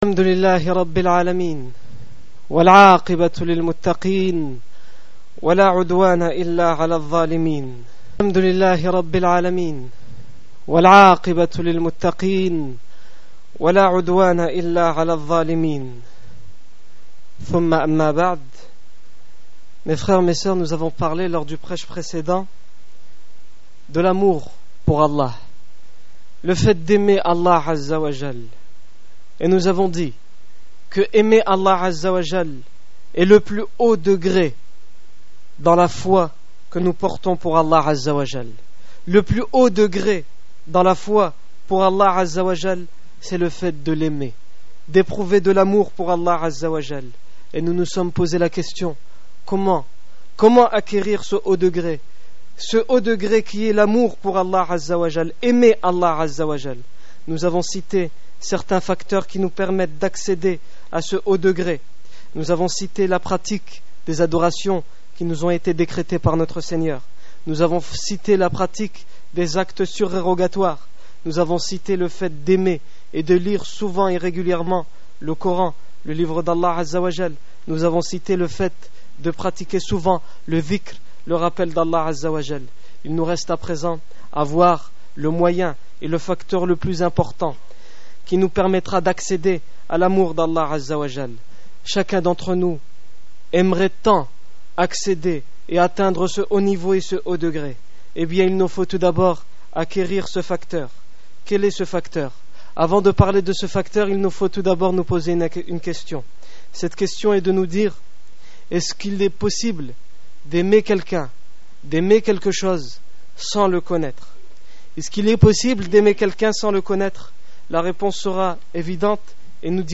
Discours du 6 mars 2009
Accueil Discours du vendredi Discours du 6 mars 2009 Comment accéder à l'amour d'Allah?